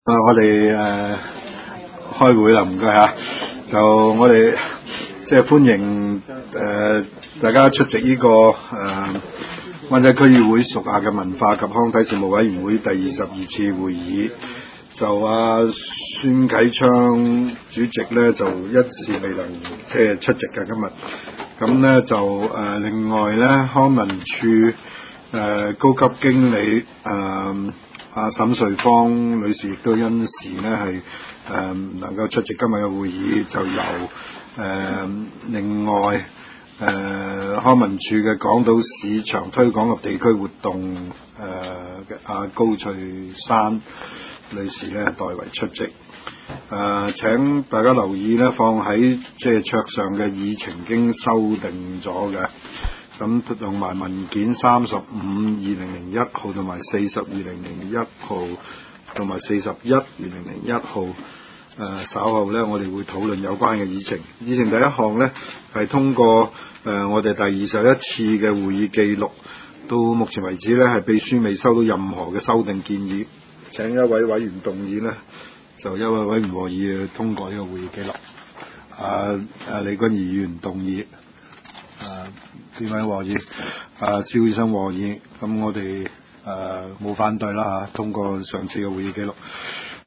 灣仔民政事務處區議會會議室